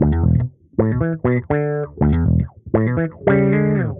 Index of /musicradar/dusty-funk-samples/Bass/120bpm